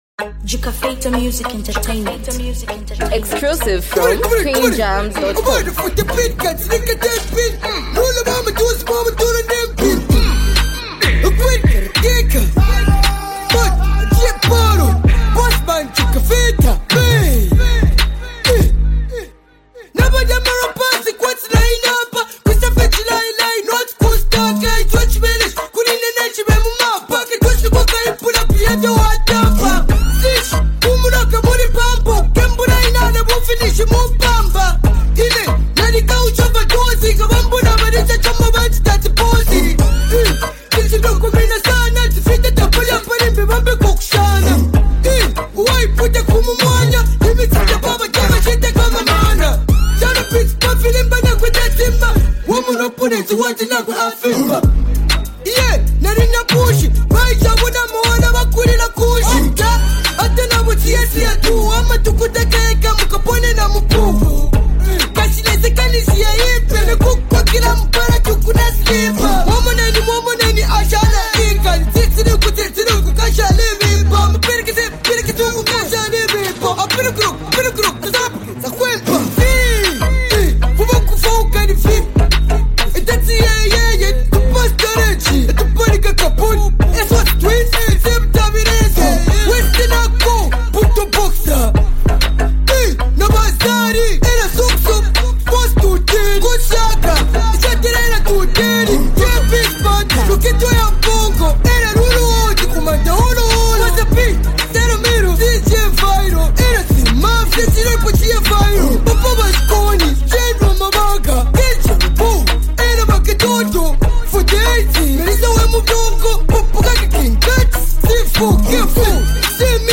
raw street expression